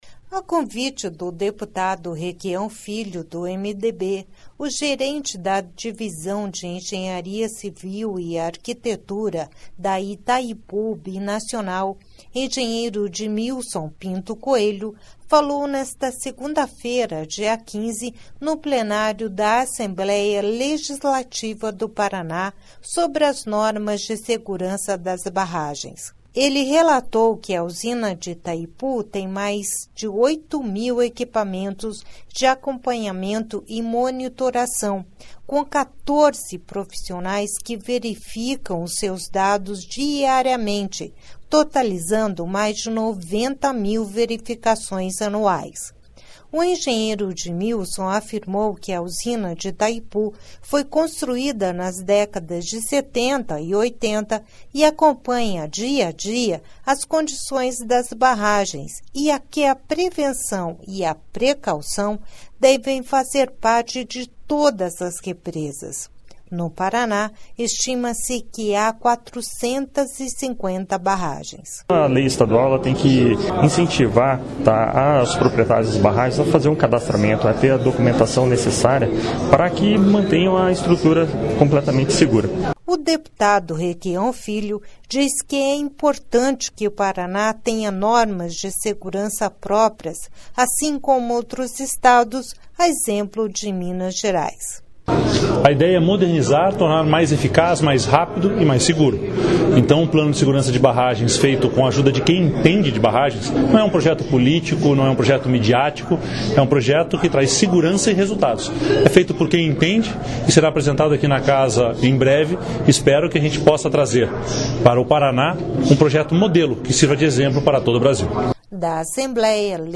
Sonora Requião Filho